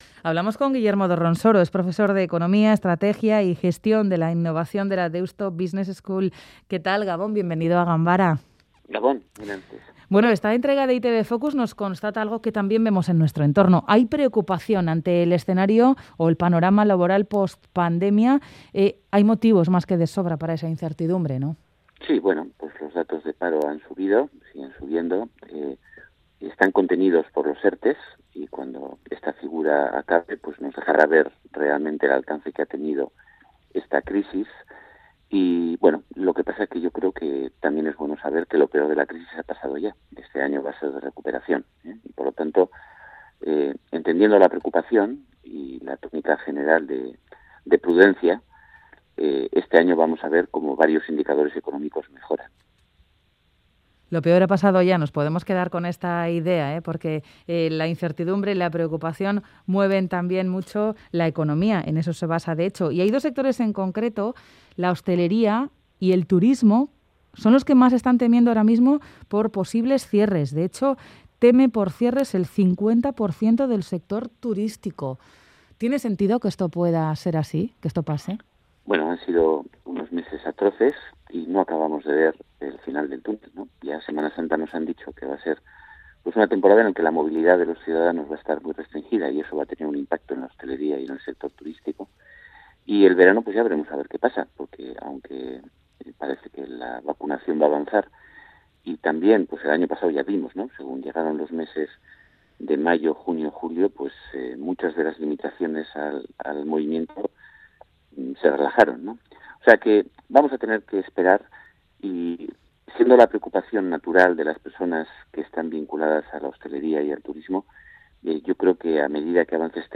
Entrevista Ganbara.